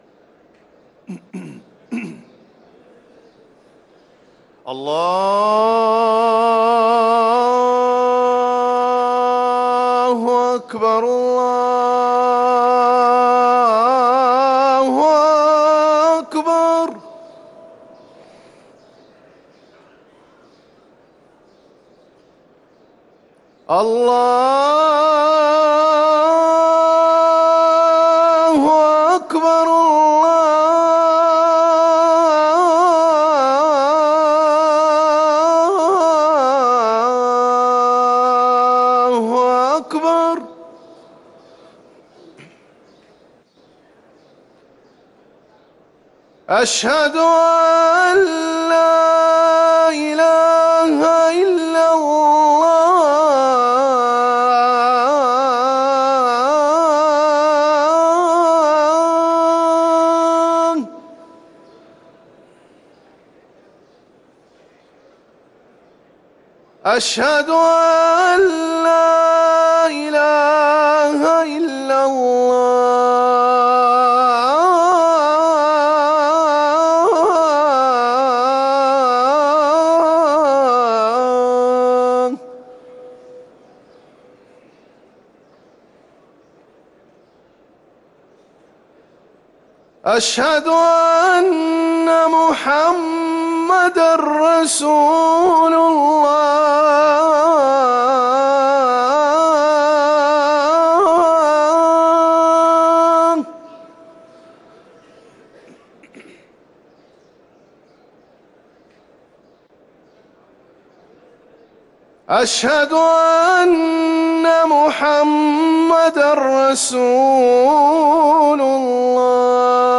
أذان الظهر
ركن الأذان